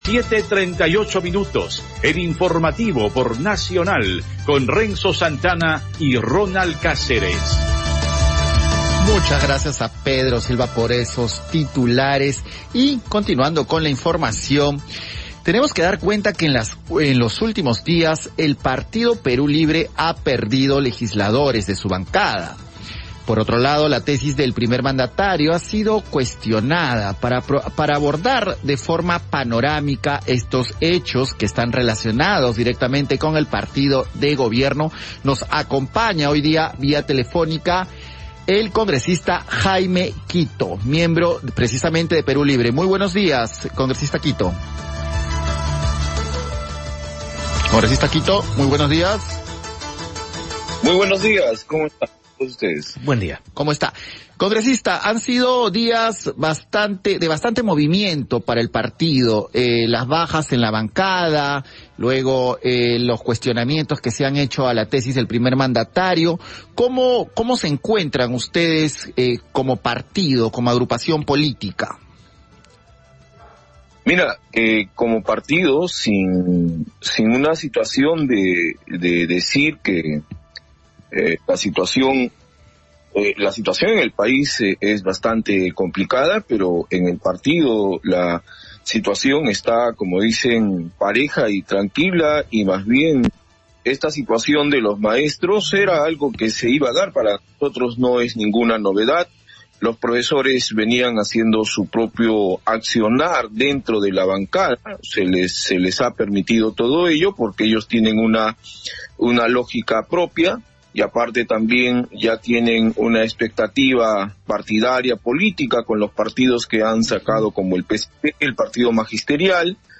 Entrevista al congresista de Perú Libre, Jaime Quito